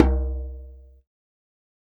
Hand Djmbe 01.wav